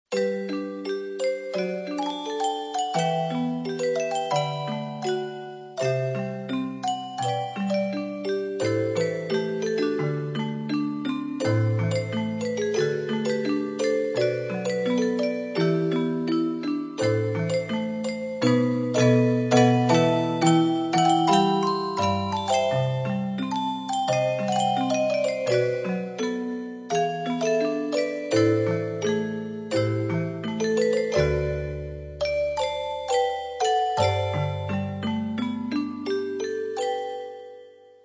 Music Box (Format:mp3,56kBit/s,24kHz,Stereo Size:261kB)